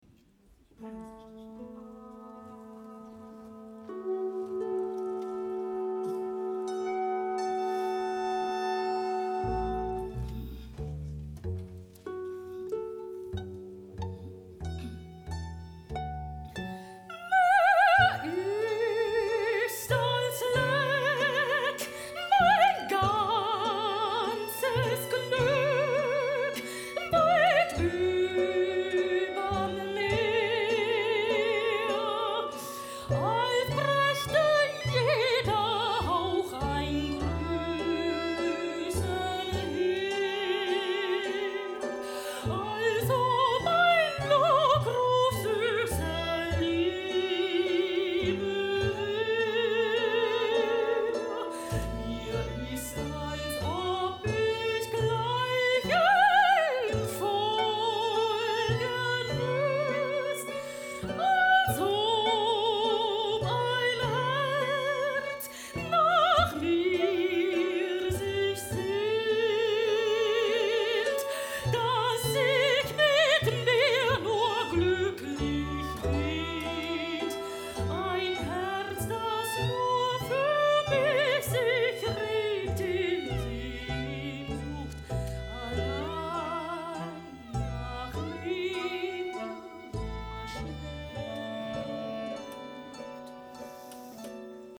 Eine Hybridoperette mit der Musicbanda Franui (2024)